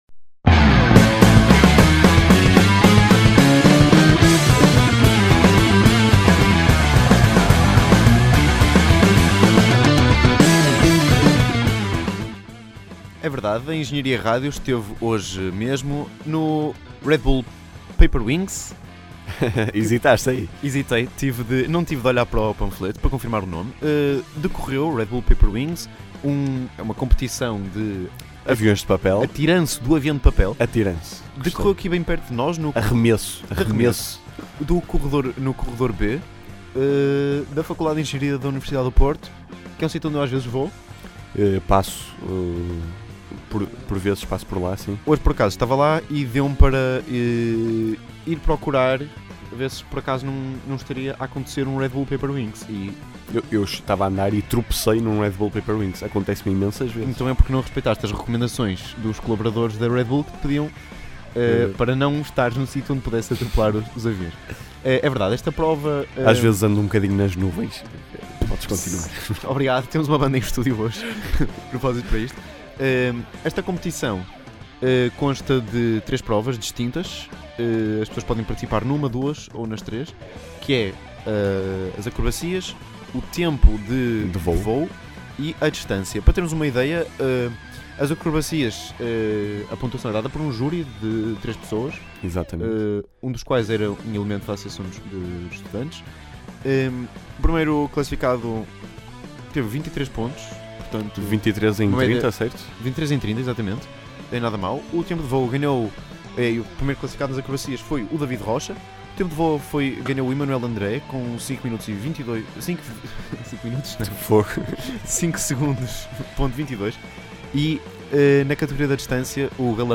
Decorreu hoje a etapa do Porto do Red Bull Paper Wings na FEUP, e a Engenharia Rádio esteve lá! Ouve aqui a nossa reportagem.